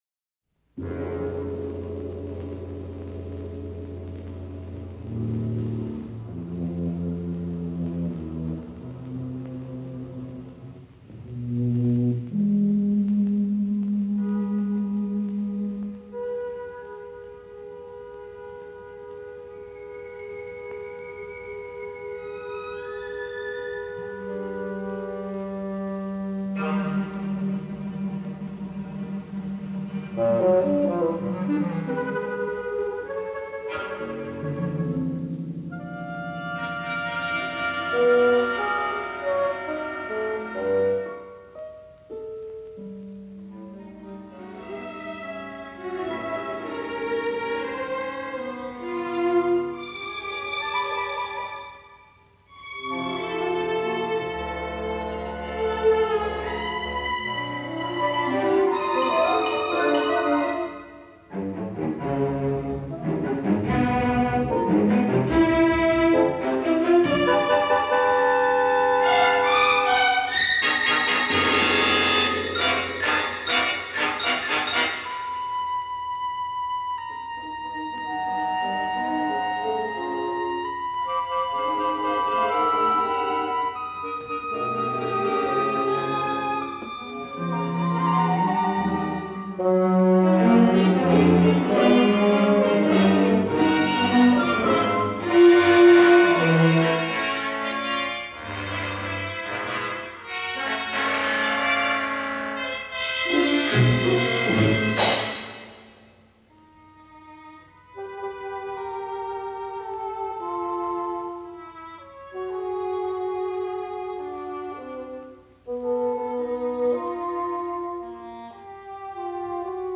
Dramatic Vocalise Database
The work consists of an introduction, a fugato, and a coda.